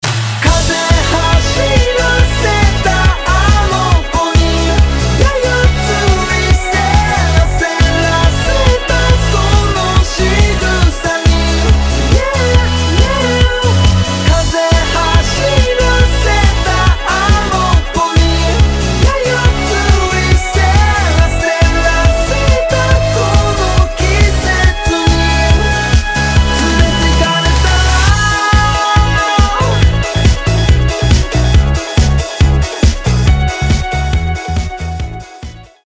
• Качество: 320, Stereo
мужской вокал
заводные
Pop Rock
j-rock
Японский Поп-Рок